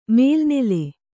♪ mēlnele